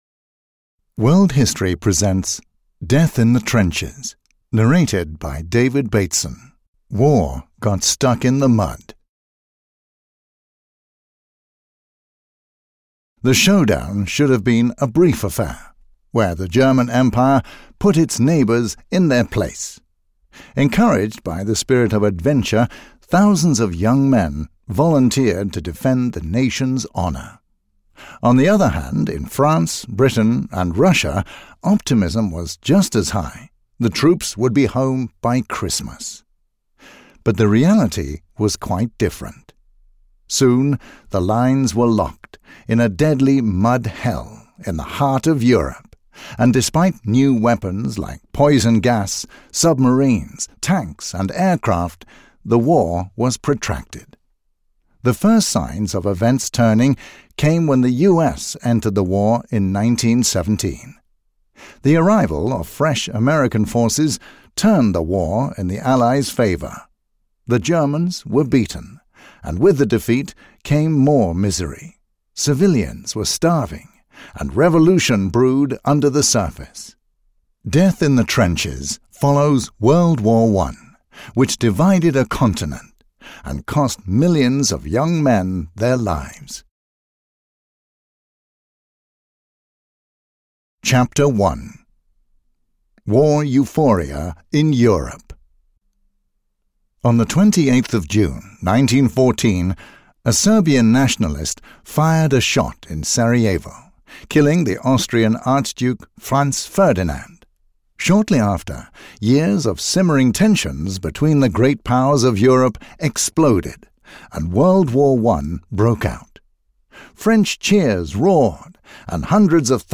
Death in the Trenches (EN) audiokniha
Ukázka z knihy